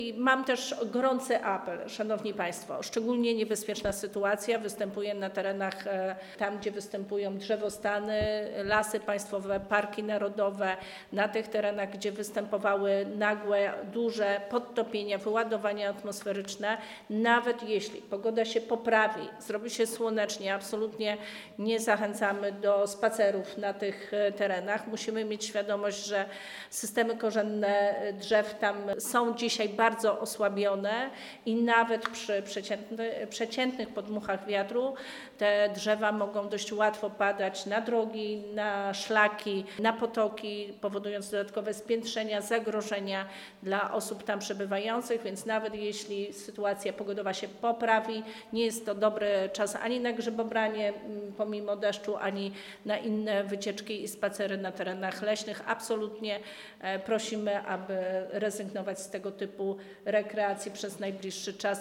Minister Klimatu i Środowiska wystosowała też apel.